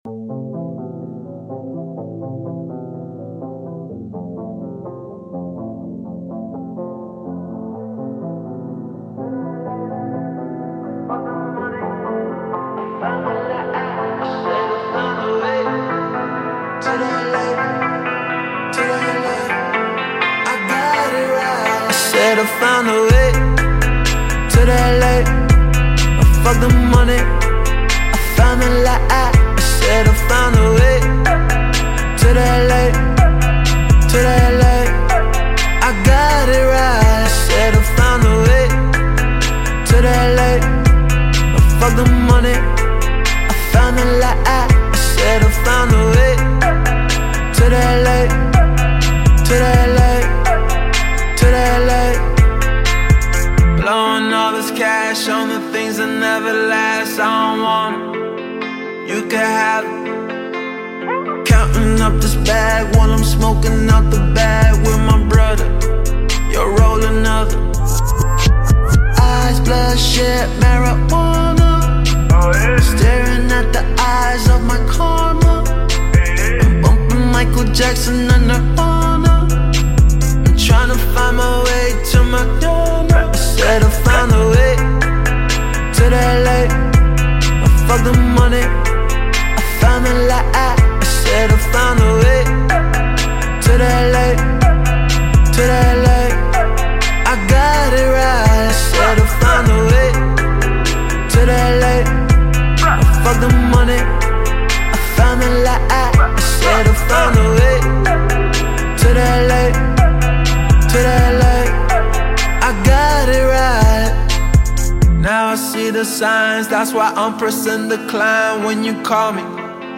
دانلود آهنگ خارجی بیس دار مخصوص سیستم 2020